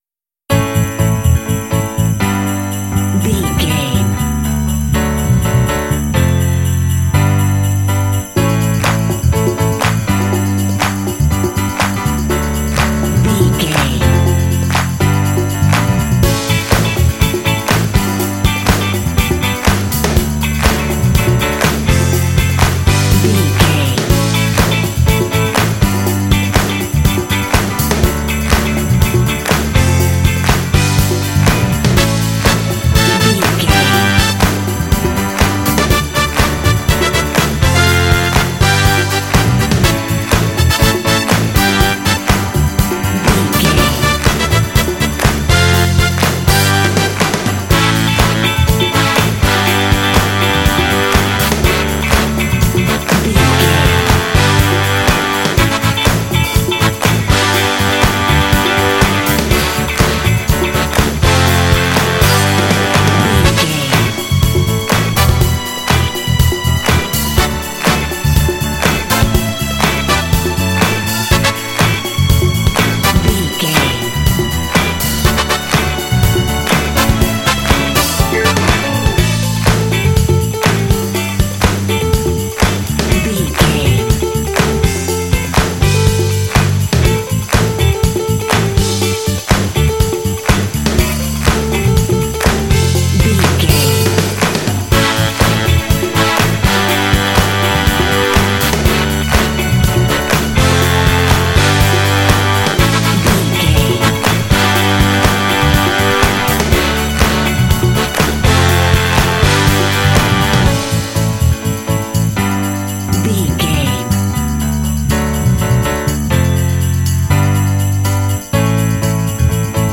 Mixolydian
bouncy
energetic
groovy
drums
bass guitar
piano
strings
saxophone
brass
big band